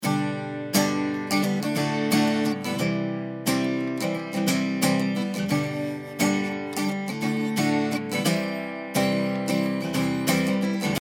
24dB/octではこうなります。
音の軽さを通り越して少し頼りない気もしますね。